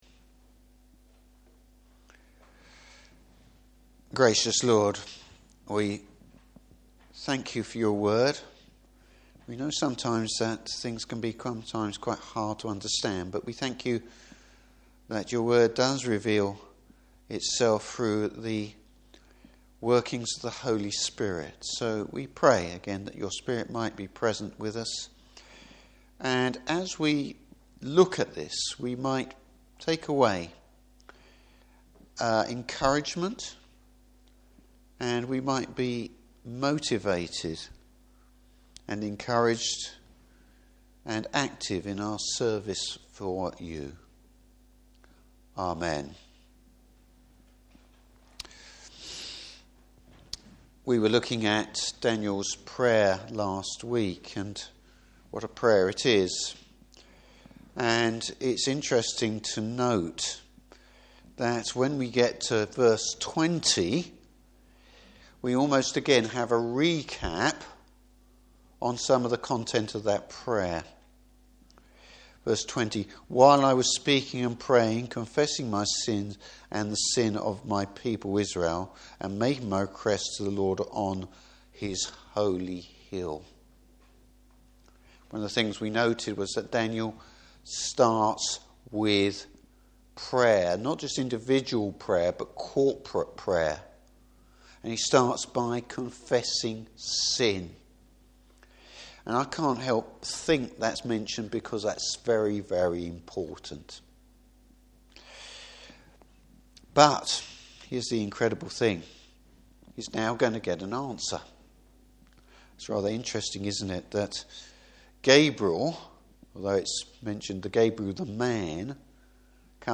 Service Type: Evening Service Whatever happens in history, God is in control.